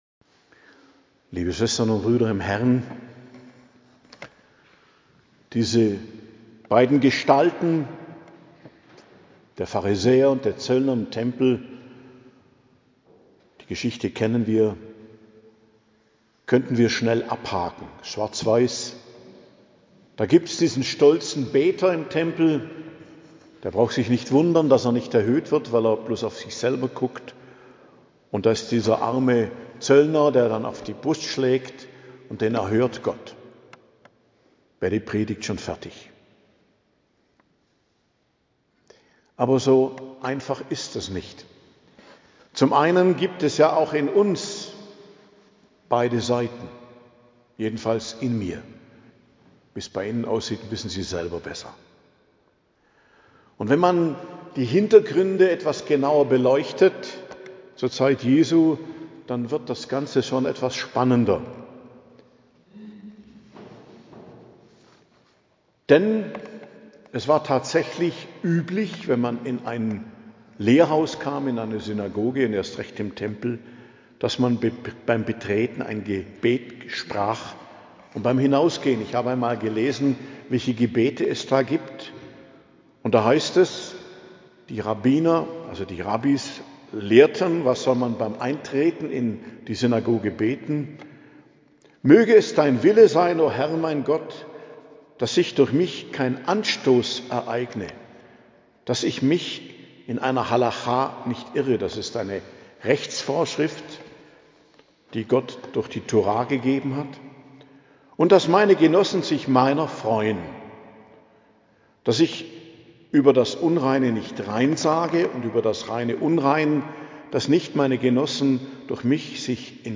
Predigt zum 30. Sonntag i.J., 26.10.2025 ~ Geistliches Zentrum Kloster Heiligkreuztal Podcast